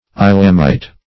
islamite - definition of islamite - synonyms, pronunciation, spelling from Free Dictionary Search Result for " islamite" : The Collaborative International Dictionary of English v.0.48: Islamite \Is"lam*ite\, n. A Mohammedan.